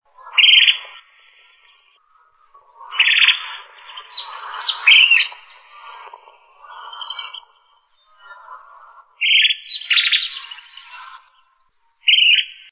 Narrow-billed Woodcreeper (Lepidocolaptes angustirostris)
Life Stage: Adult
Location or protected area: Reserva Ecológica Costanera Sur (RECS)
Condition: Wild
Certainty: Photographed, Recorded vocal